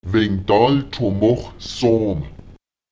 Die Imperativausdrücke -pom, -ral und -sóm können hervorgehoben werden, indem man sie anstatt ihrer grammatikalisch korrekten Position als Verbsuffixe im Prädikat nach einem Lith () am Ende des Satzes anbringt und sie kräftiger artikuliert: